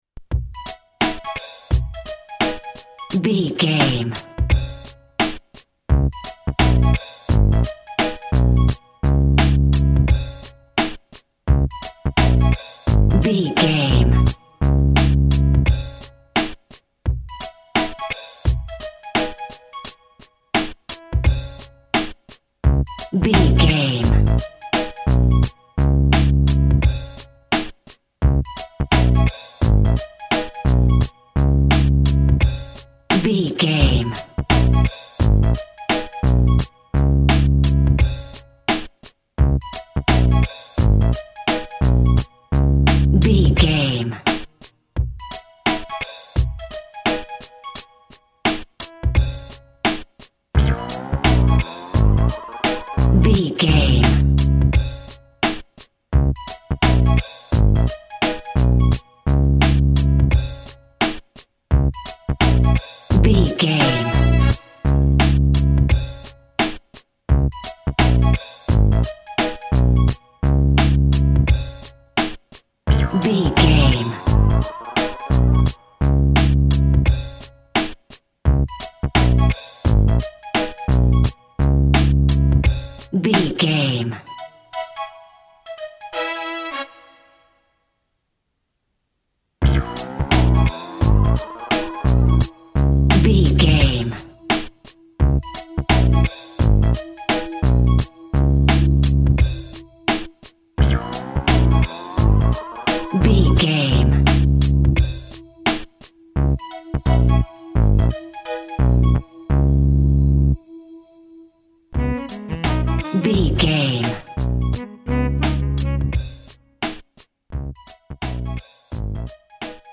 Aeolian/Minor
C#
synth lead
synth bass
hip hop synths
electronics